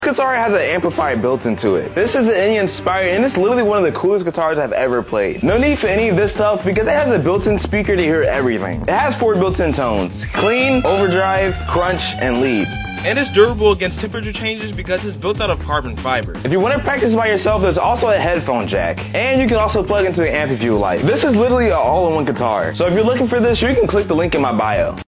Electric Guitar With Built-In Speaker